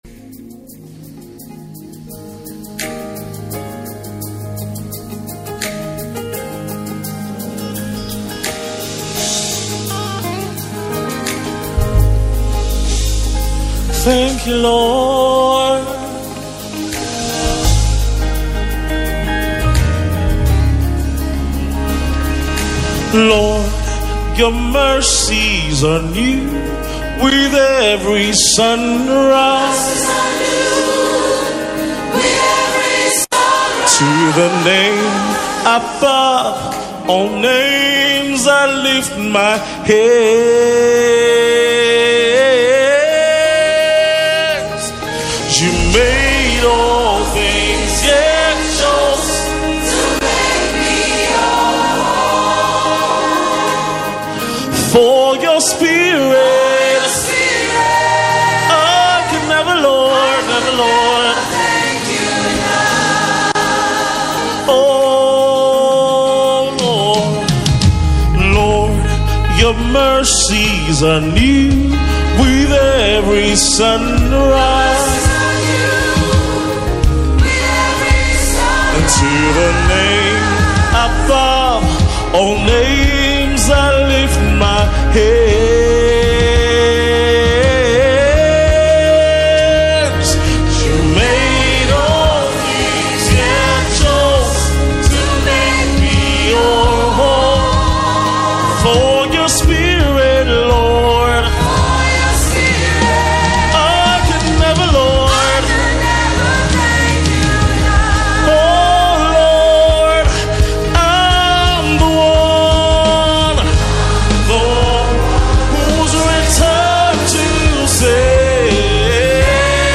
Key – A